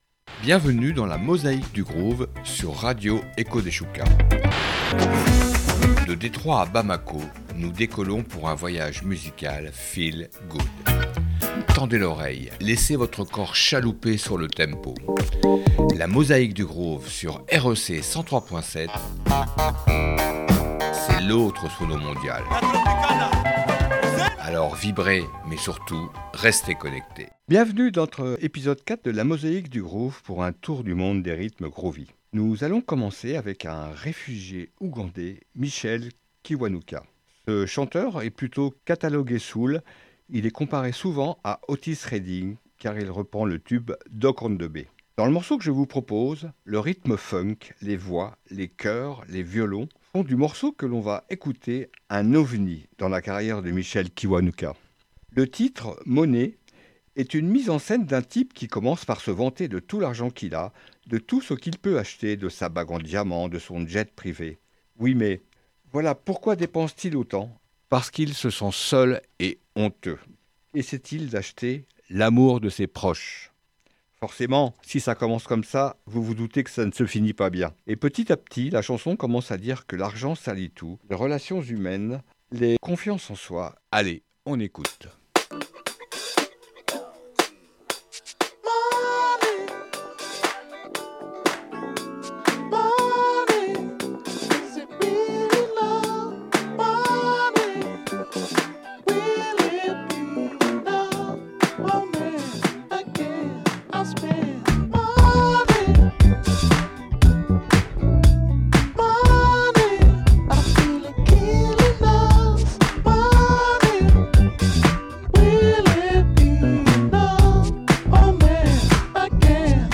des morceaux groovis
du riff, du live